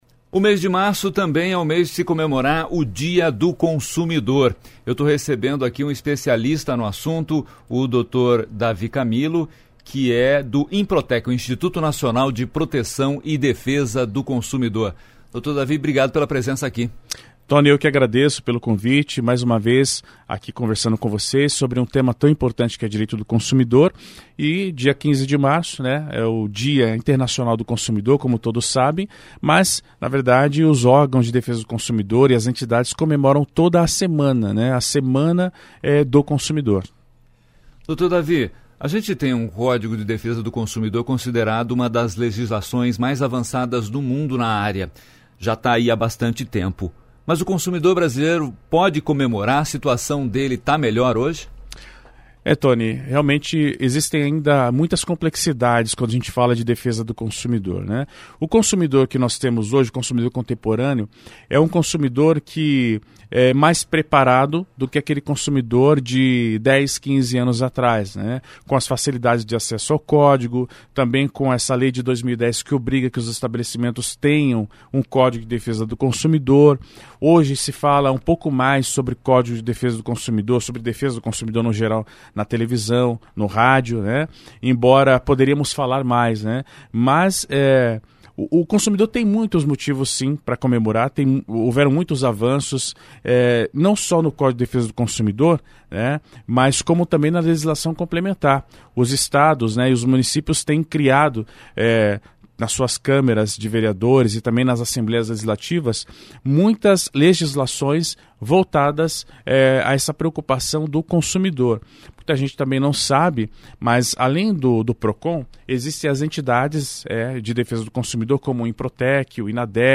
Entrevista - Semana do Consumidor